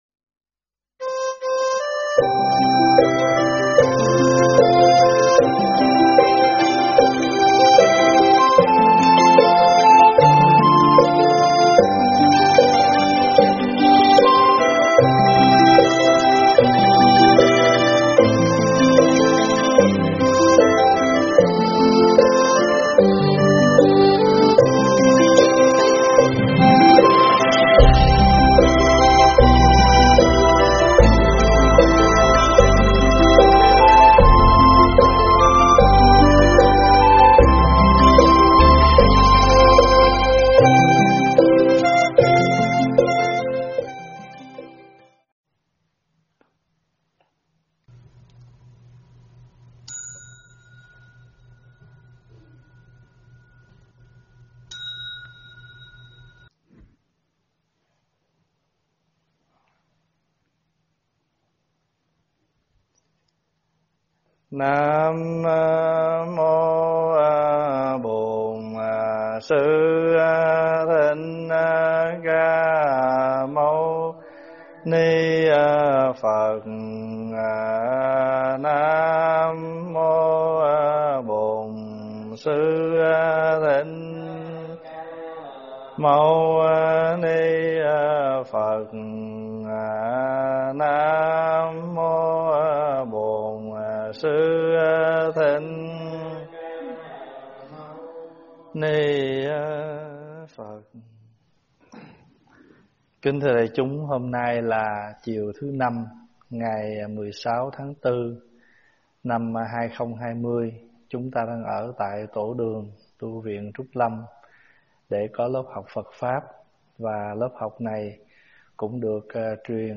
tại Tv Trúc Lâm